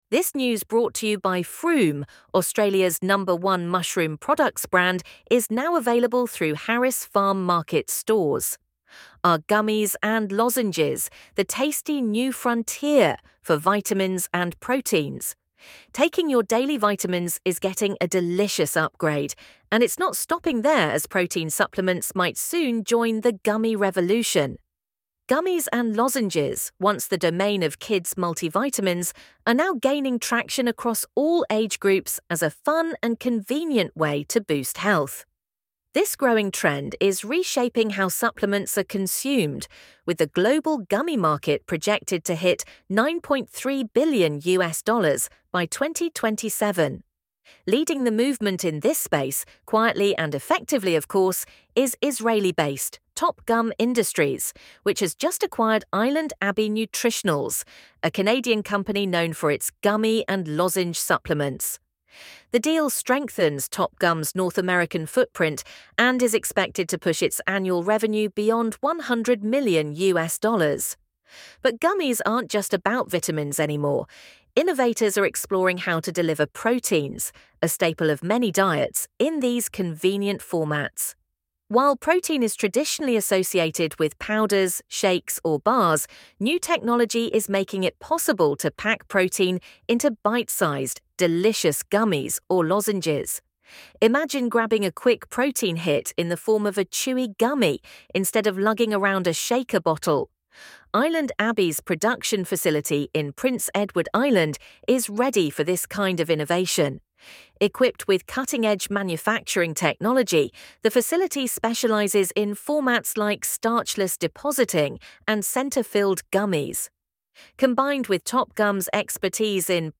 LISTEN TO THIS AUDIO ARTICLE Taking your daily vitamins is getting a delicious upgrade, and it’s not stopping there as protein supplements might soon join the gummy revolution.